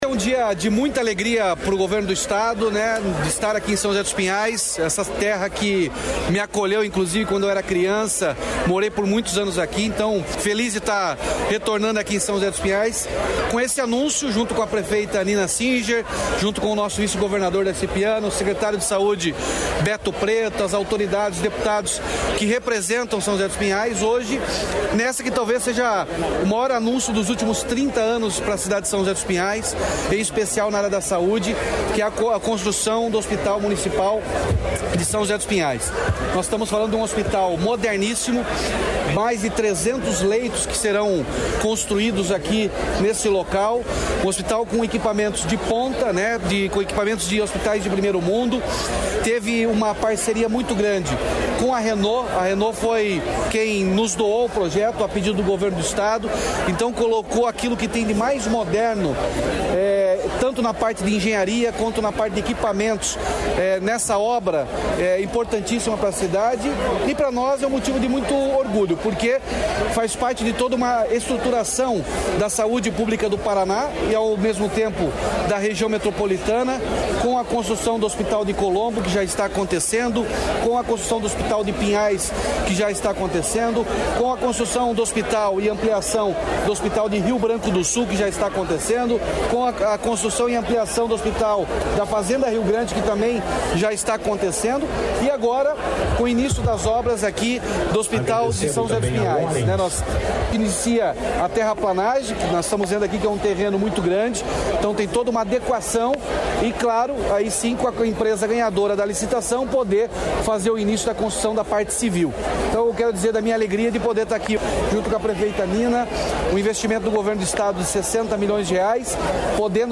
Sonora do governador Ratinho Junior sobre o anúncio da liberação de recursos para hospital em São José dos Pinhais